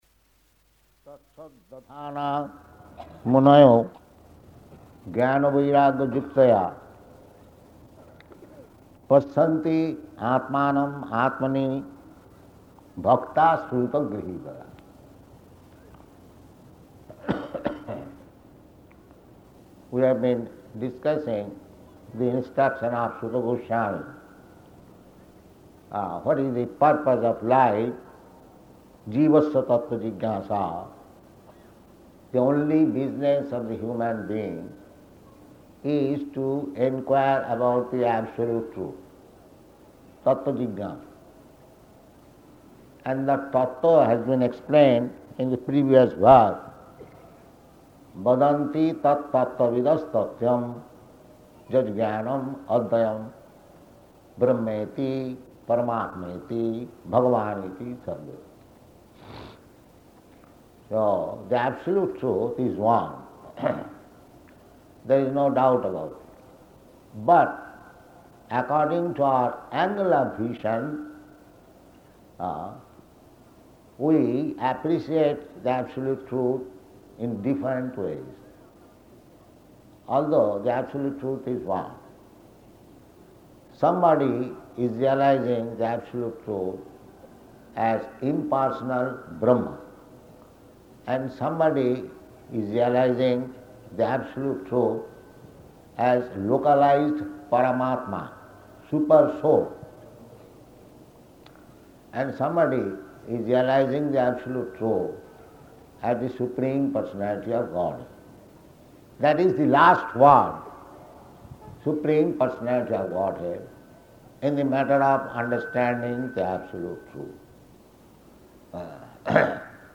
Location: Delhi